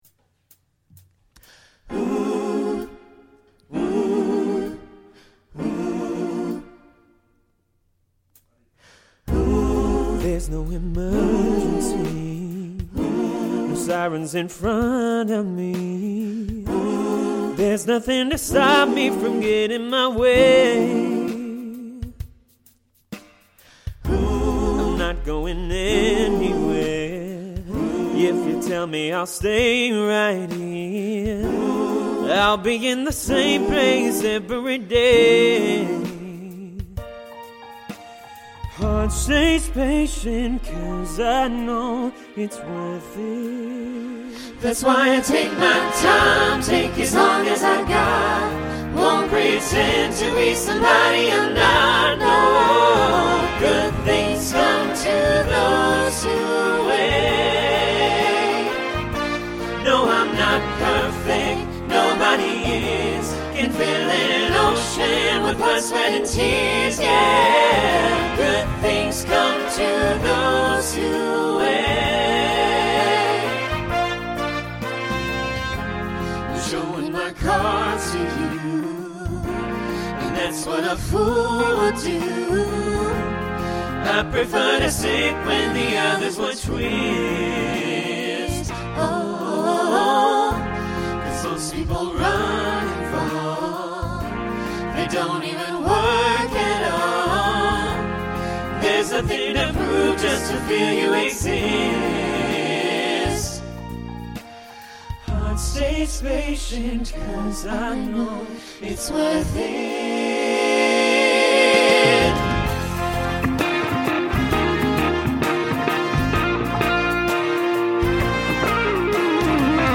Voicing SATB Instrumental combo Genre Folk , Rock
Mid-tempo